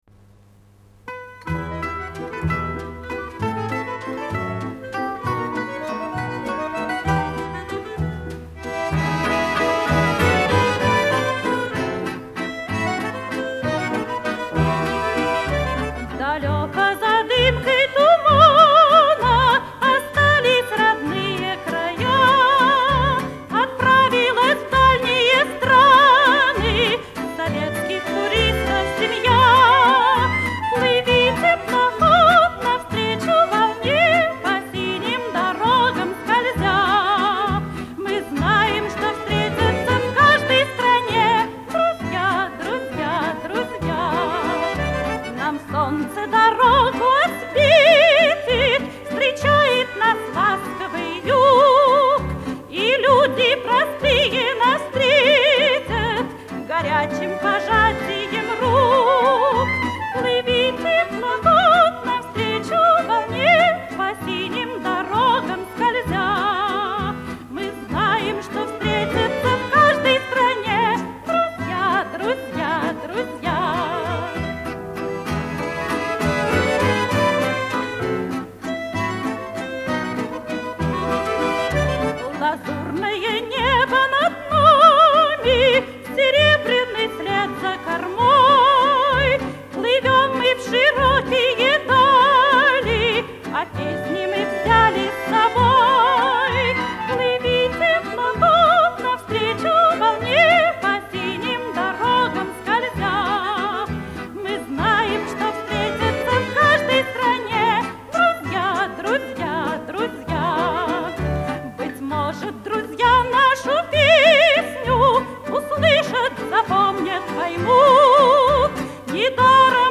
Музыка из мешка с лентами, датируемыми 1960-м годом.